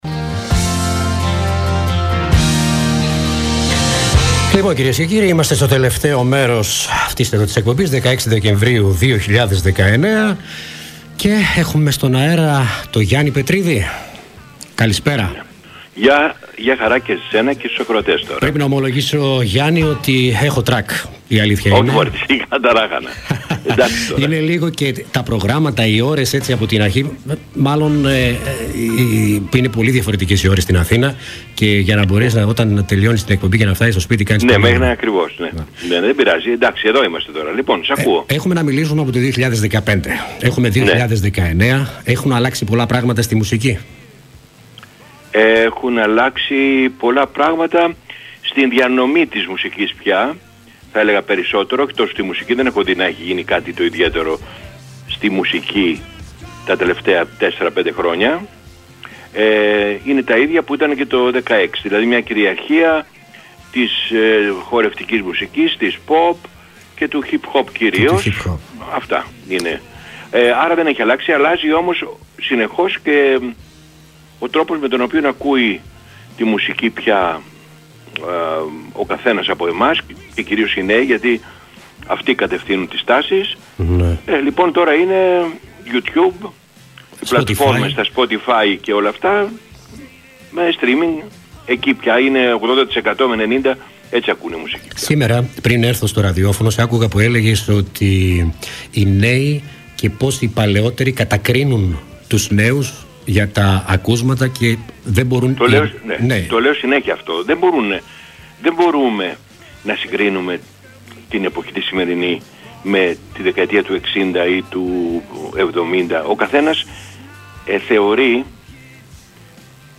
Το απόγευμα της Δευτέρας είχαμε την μεγάλη χαρά να επικοινωνήσουμε τηλεφωνικά με τον Γιάννη Πετρίδη, ο οποίος μίλησε ζωντανά στους 93.7 & στην εκπομπή του